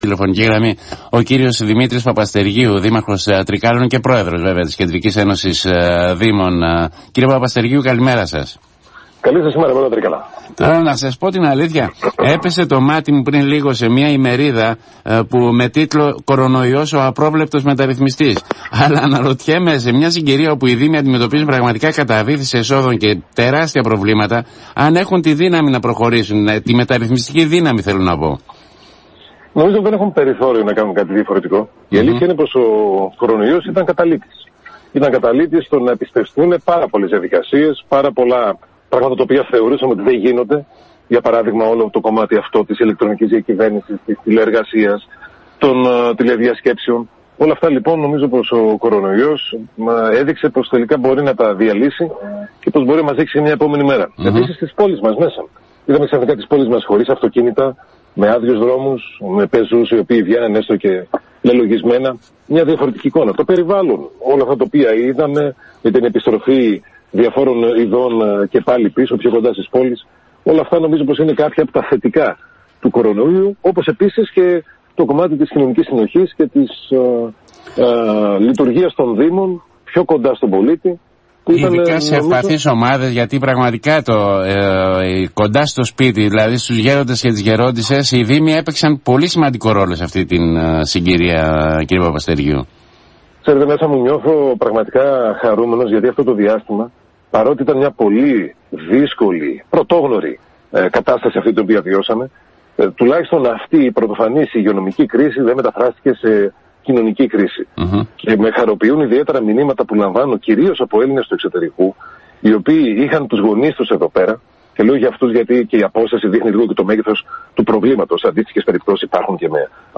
Ο επικεφαλής της ΚΕΔΕ και Δήμαρχος Τρικκαίων,  Δημήτρης Παπαστεργίου δήλωσε στο σταθμό της πόλης ότι « ο Μάιος είναι μια καλή στιγμή να ξαναδούμε τα ζητήματα των πόλεων διαφορετικά  και να πάρουμε άμεσα, γενναίες αποφάσεις, μετά και την εμπειρία μας με την ελαχιστοποίηση της κίνησης, λόγω καραντίνας και τη χρήση ποδηλάτων.